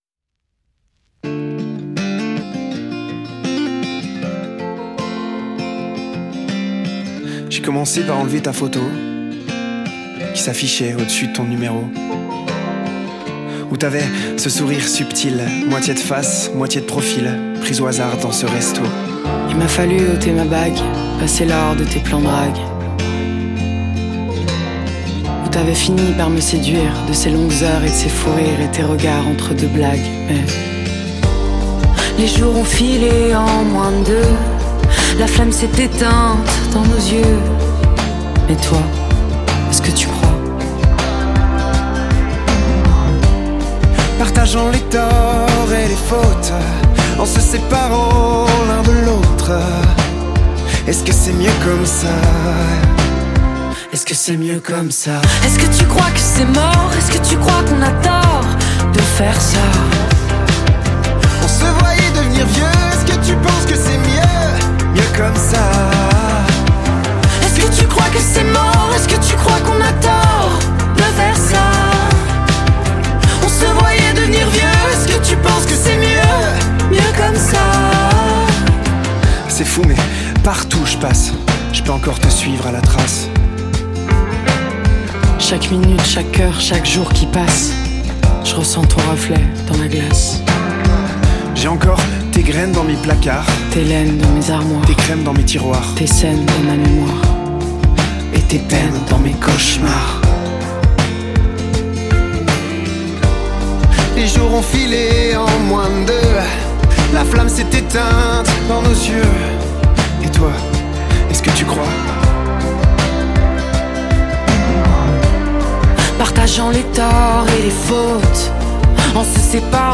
VARIETE FRANCAISE